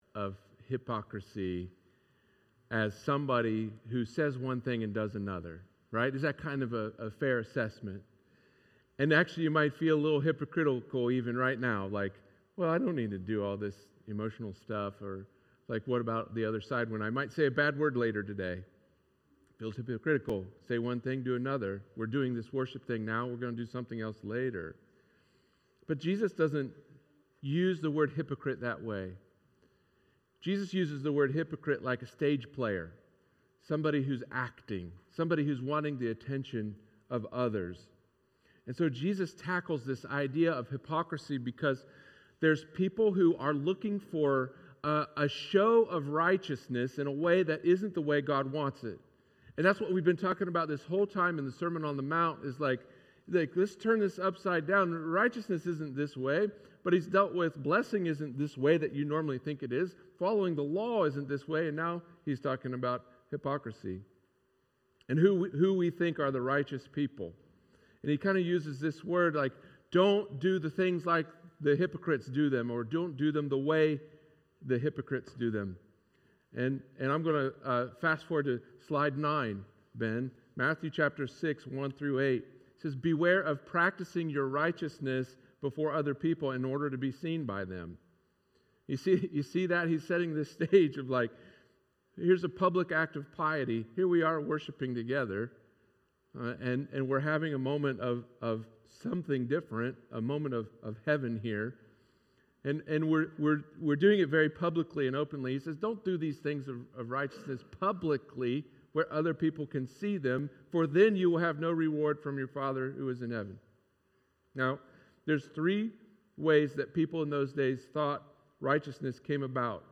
Sermon on the Mount, Triplet of Hypocrites - Engage Newark
sermon-on-the-mount-triplet-of-hypocrites.mp3